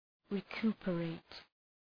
Προφορά
{rı’ku:pə,reıt}